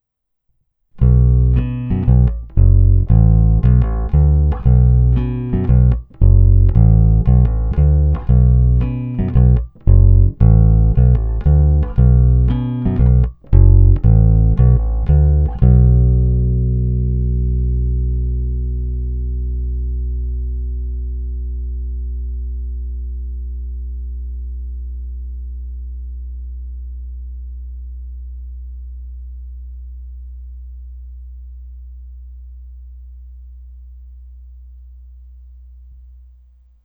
Hra u krku
Parádní tučný, pevný, zvonivý zvuk s těmi správnými středy, které tmelí kapelní zvuk a zároveň dávají base vyniknout.